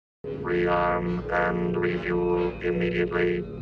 BSG_Centurion_-_Rearm_and_refuel.wav